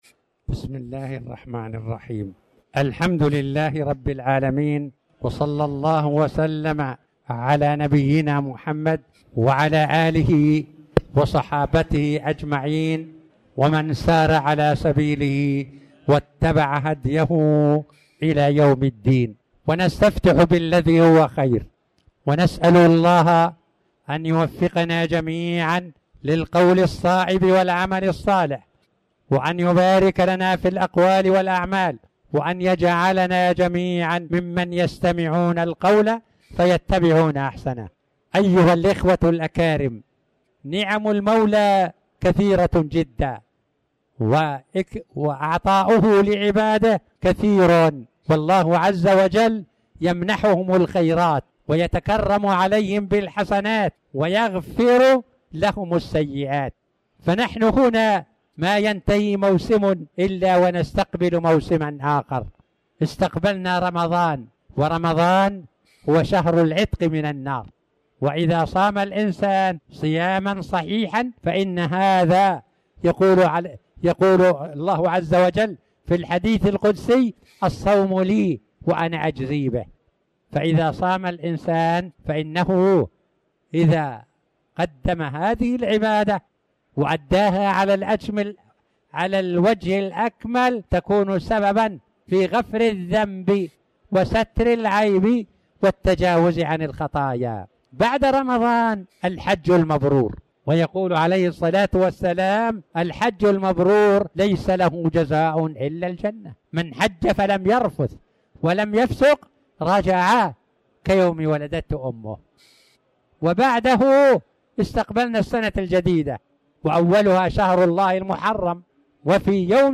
تاريخ النشر ٩ محرم ١٤٤٠ هـ المكان: المسجد الحرام الشيخ